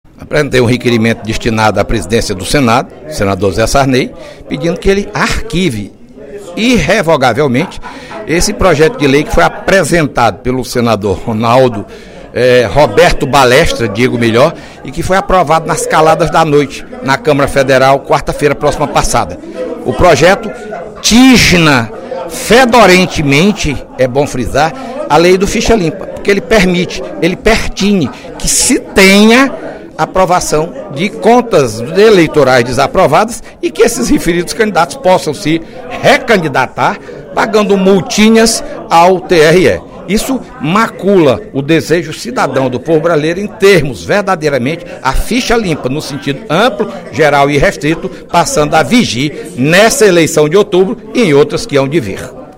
Durante pronunciamento na sessão plenária desta sexta-feira (25/05), o deputado Fernando Hugo (PSDB) conclamou a sociedade e os “políticos de bem” a impedirem a aprovação, pelo Senado, do projeto de lei 3.839 que pretende facilitar a prestação de contas de campanhas políticas.